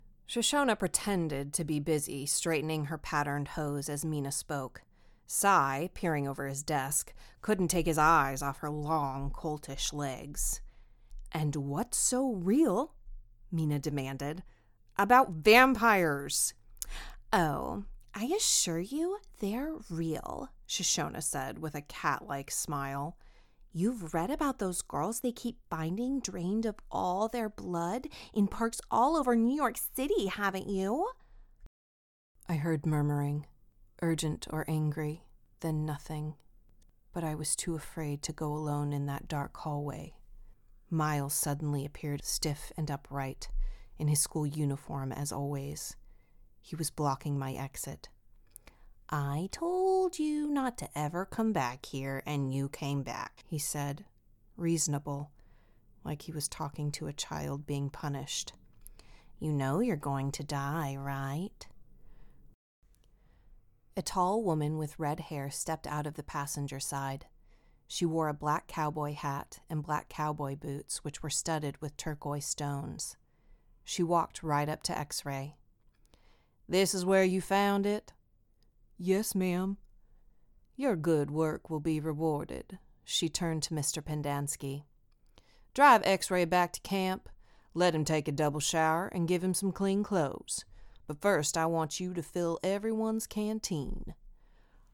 Playing age: Teens - 20s, 20 - 30sNative Accent: North AmericanOther Accents: American, Australian, Irish, London, RP, Scottish
• Native Accent: American Standard, Texan